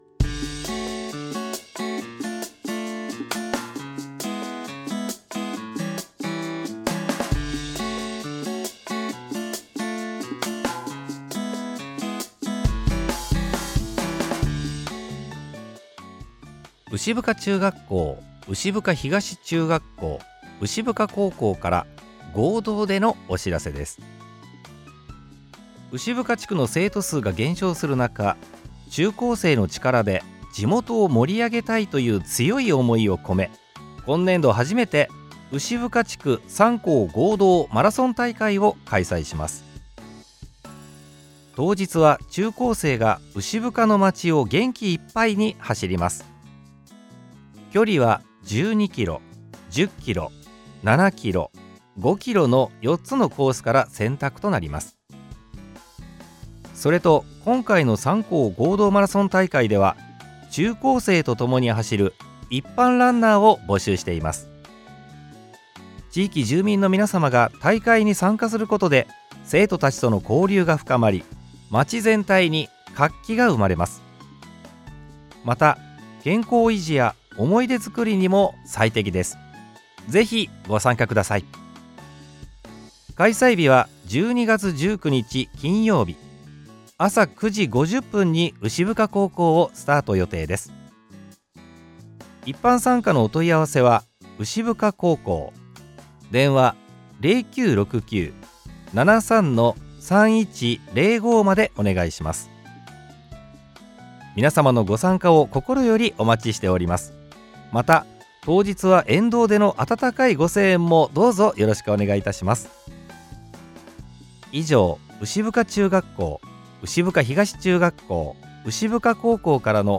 今週末に牛深中学校、牛深東中学校、牛深高校の３校合同で実施する マラソン大会をみつばちラジオにて宣伝していただいています！